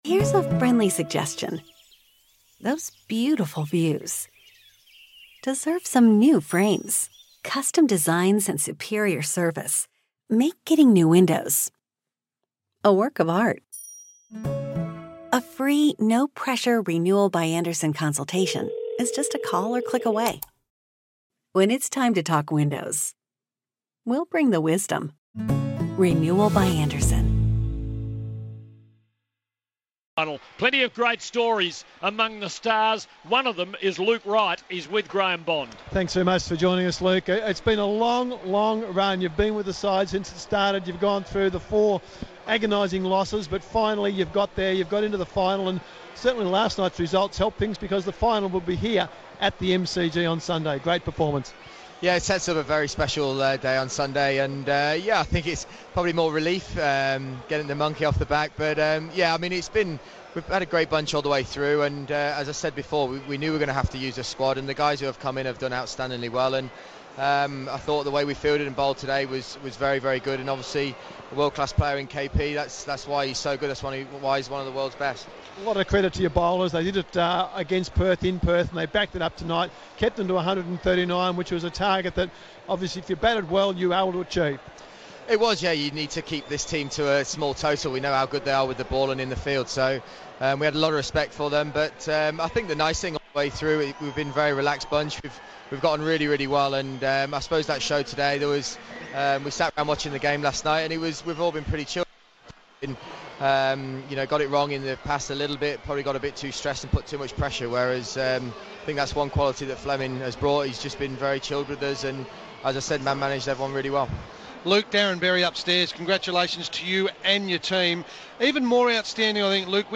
INTERVIEW: Luke Wright speaks after the Melbourne Stars BBL05 semi-final win over Perth Scorchers.